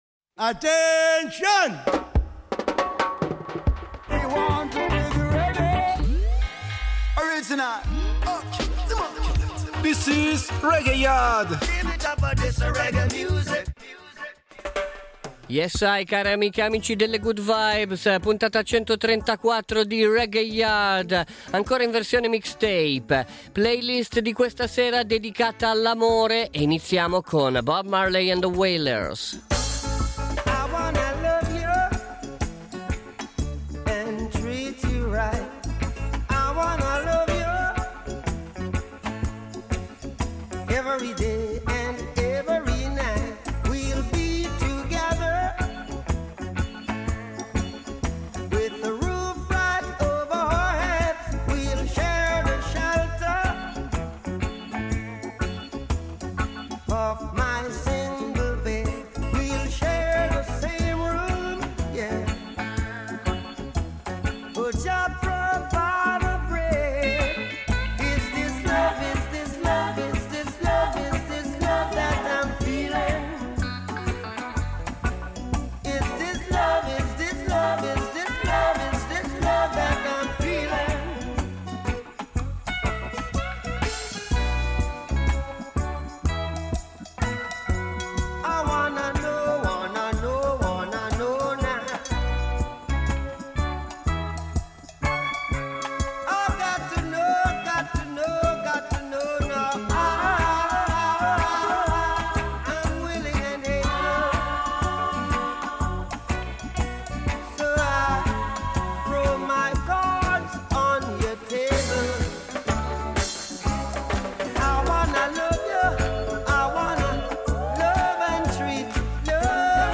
Reggae lovers
REGGAE / WORLD